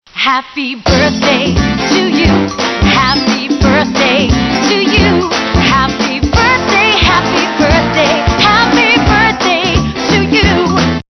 Happy Birthday To You, Singing Balloon, 28″
Plays ” Happy Birthday”